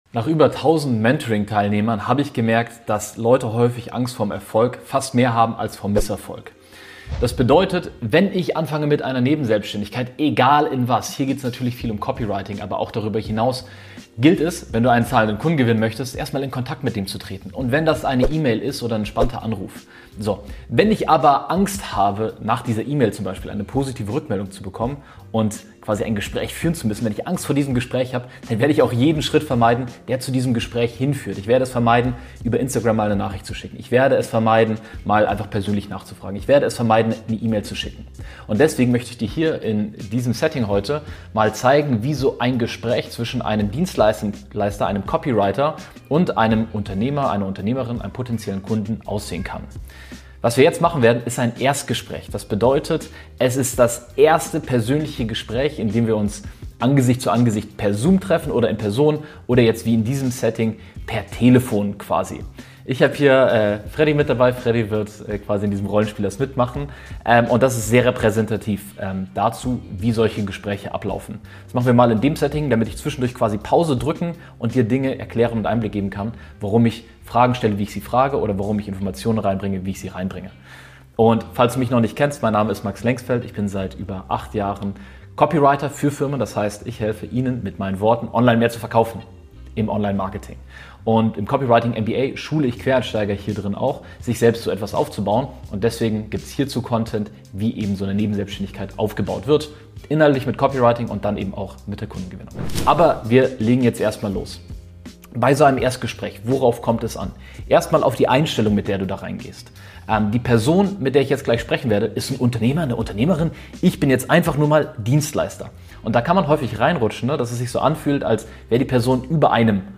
Der erste Kunde: Live Sales Rollenspiel als Copywriter ~ Copywriting MBA Podcast